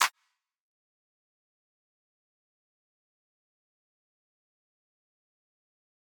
SouthSide Clap (12).wav